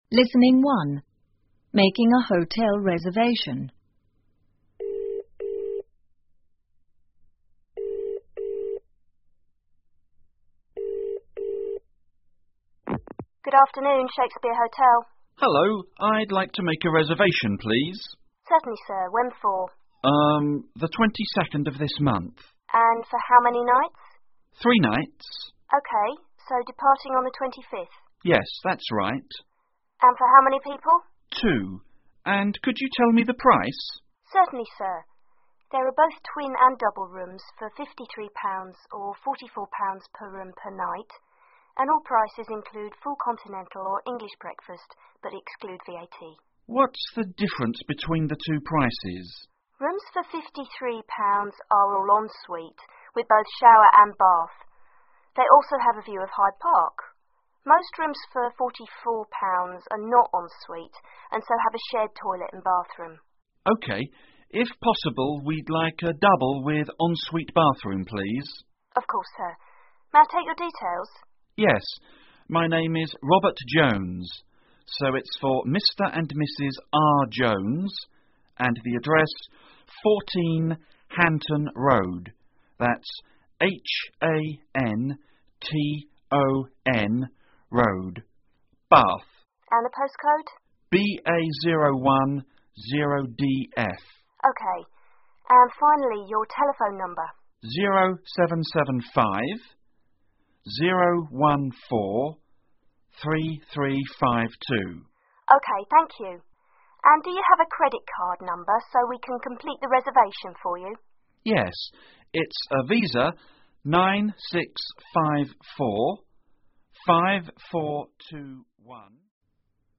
（电话铃声）